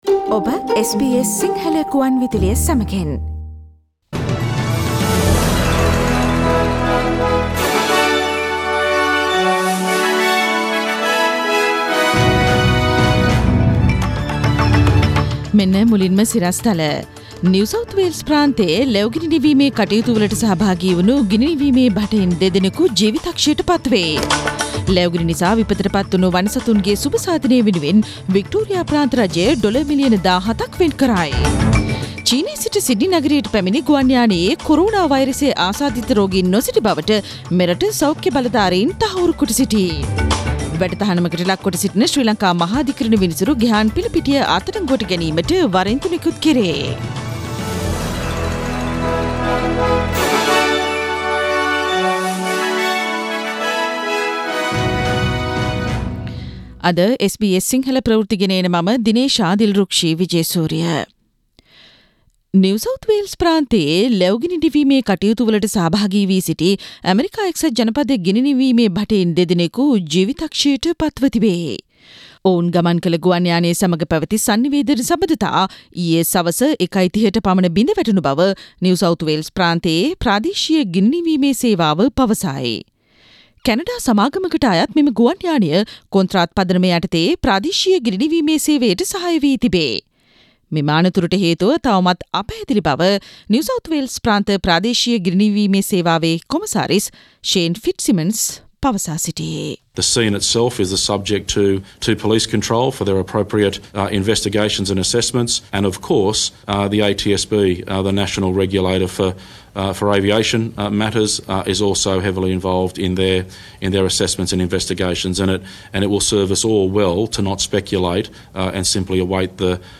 SBS Sinhala news